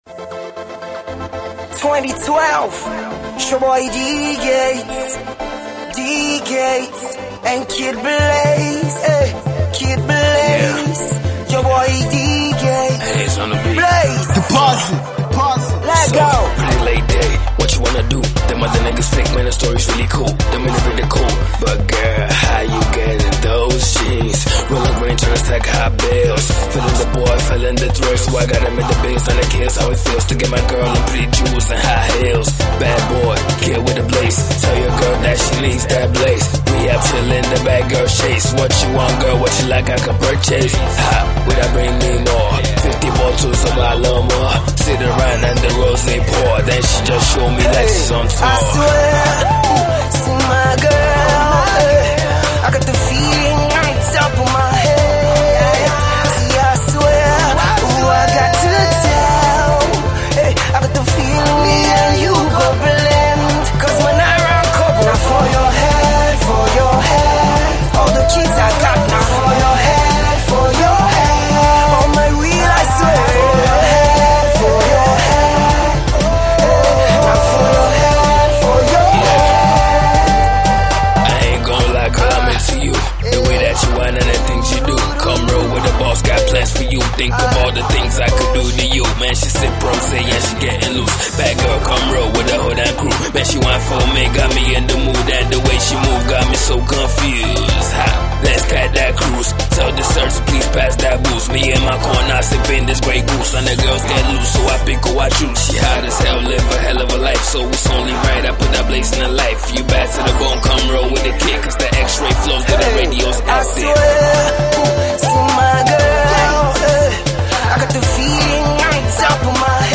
the almost-slow jam is full of potential.